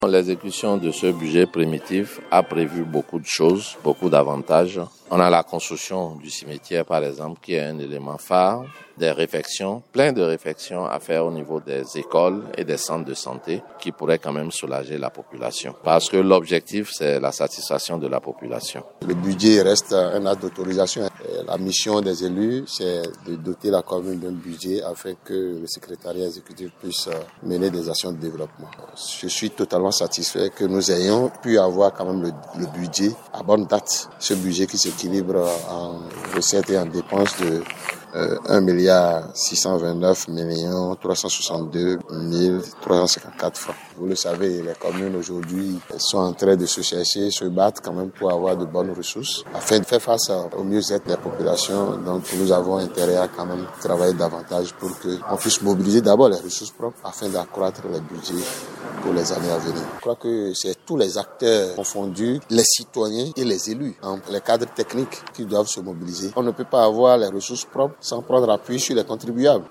Les conseillers communaux et cadres techniques sortent satisfaits de cette session qui engage davantage la commune de Grand-Popo sur la voix de développement. Voici leurs impressions